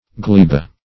Search Result for " gleba" : Wordnet 3.0 NOUN (1) 1. fleshy spore-bearing inner mass of e.g. a puffball or stinkhorn ; The Collaborative International Dictionary of English v.0.48: Gleba \Gle"ba\, n.; pl.